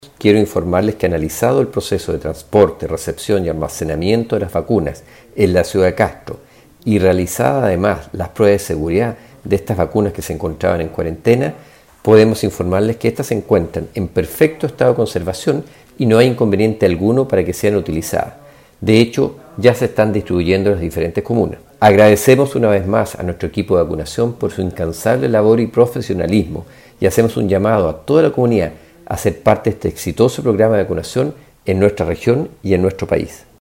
Esto fue lo señalado por el seremi de Salud.